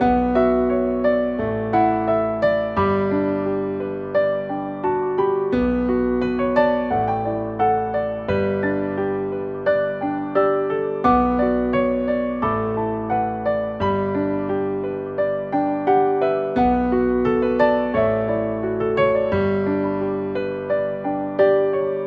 描述：安排在Fl Studio！ 和弦：C Fa Rem G
标签： 65 bpm RnB Loops Piano Loops 2.49 MB wav Key : C
声道立体声